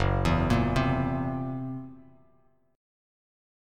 F#7b5 Chord